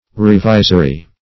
Revisory \Re*vi"so*ry\, a. Having the power or purpose to revise; revising.